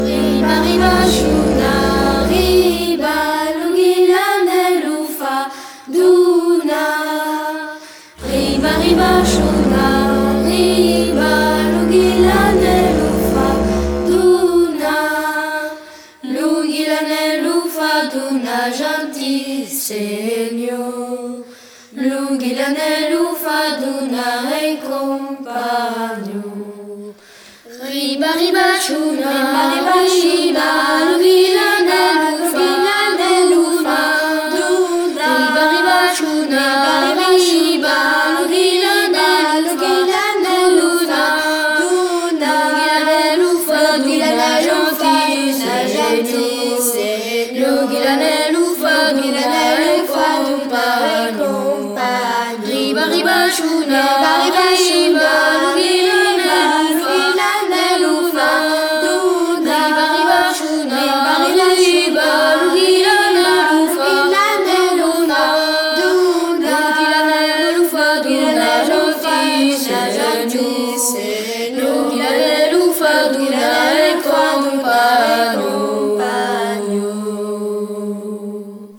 les enfants conteurs et musiciens du Limousin